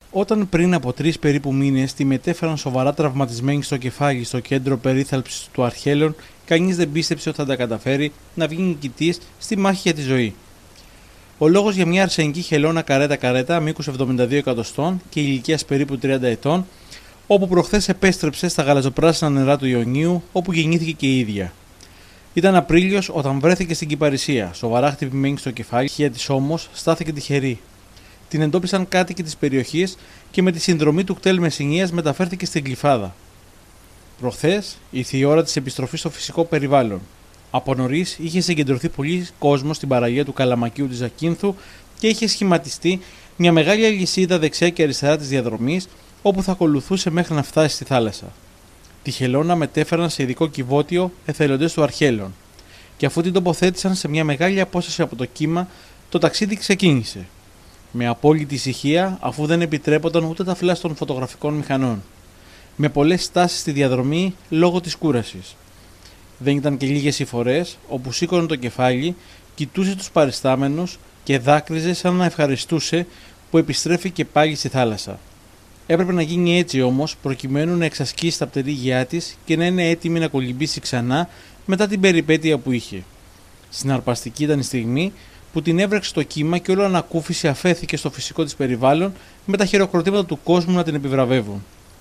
ηχητικό ντοκουμέντο.